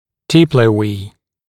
[‘dɪpləˌwiː][‘диплэˌуи:]диплоэ, губчатое вещество костей свода черепа
diploe.mp3